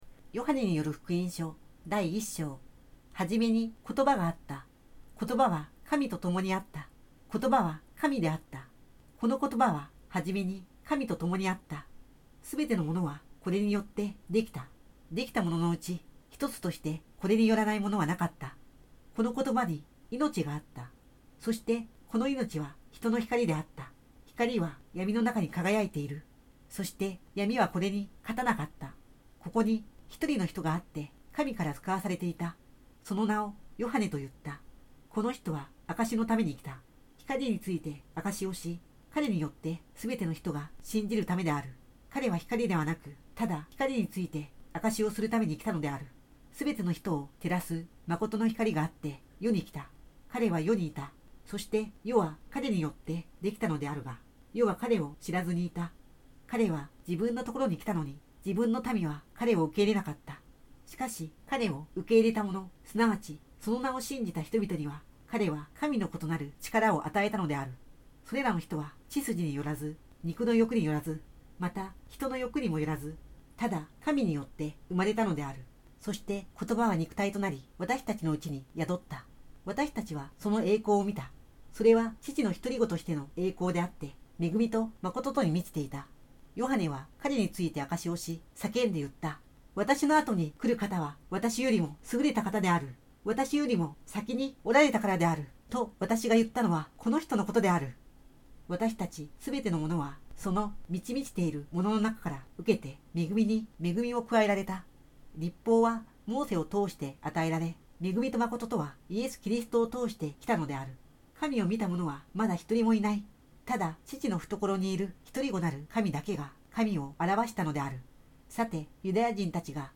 日語朗読 MP3　章 中文朗読 MP3　章 English Reading MP3　Chapter